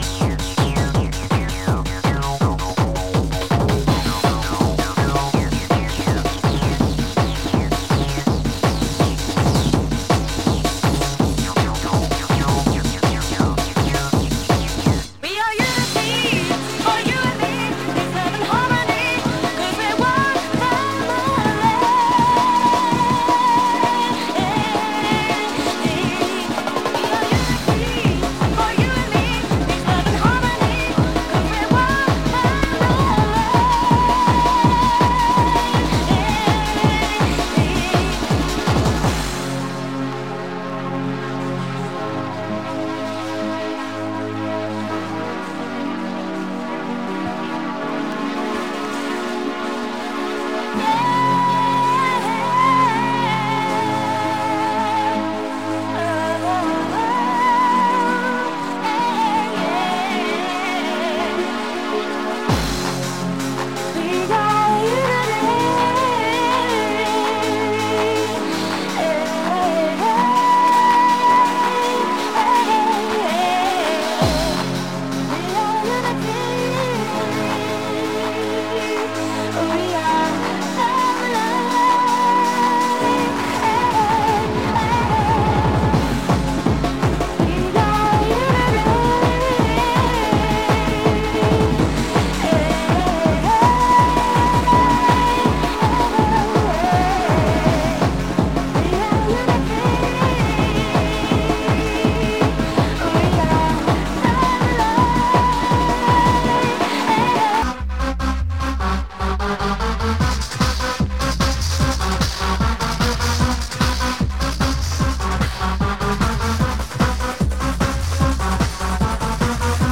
Light marks and scratches, vinyl plays to a VG+ grading